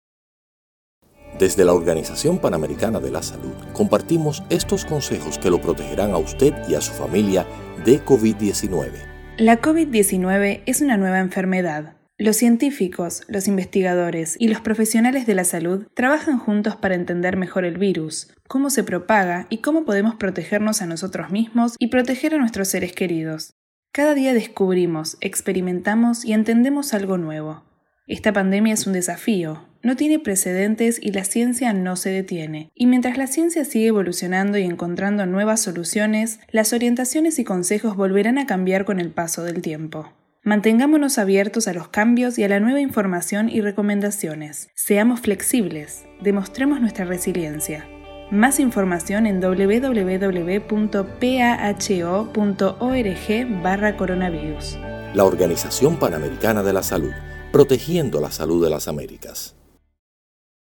Mensajes de radio sobre la prevención de COVID-19: Ciencia y COVID-19